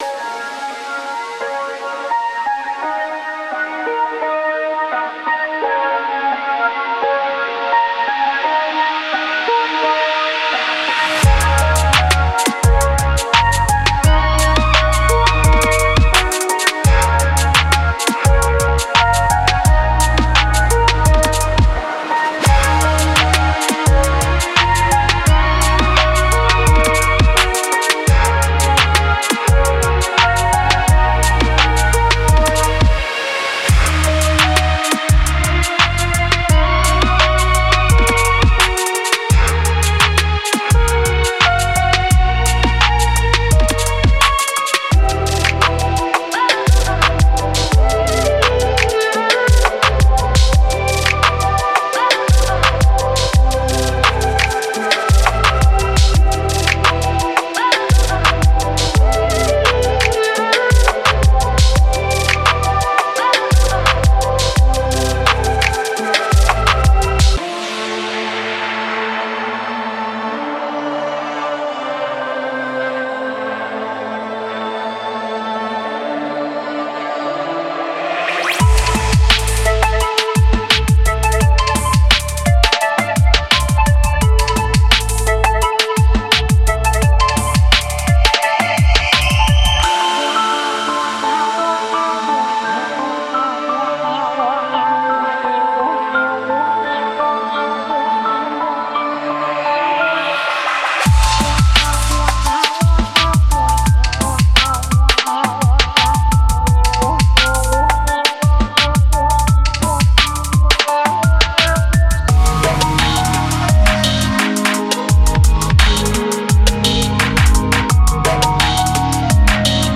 Genre:Trap
夢のようなメロディ、空間的なテクスチャ、そして力強くもリラックスしたビートを融合させたジャンル横断型のコレクションです。
デモサウンドはコチラ↓